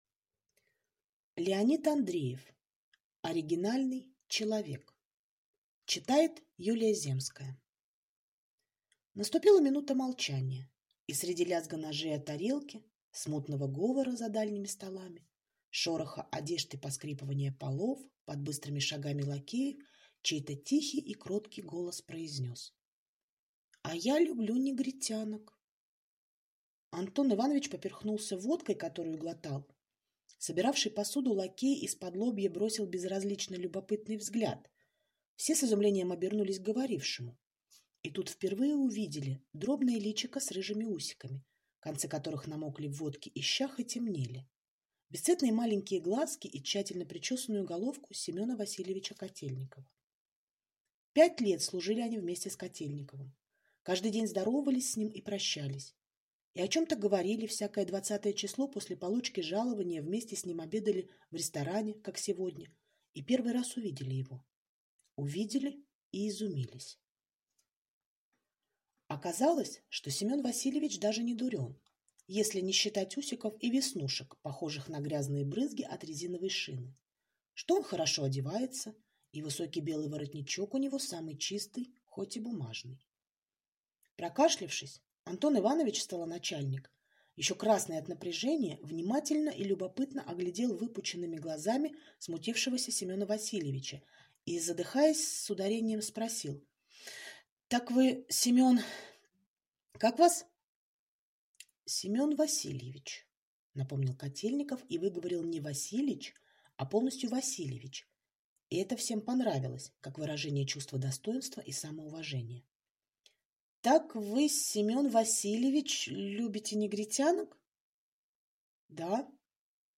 Аудиокнига Оригинальный человек | Библиотека аудиокниг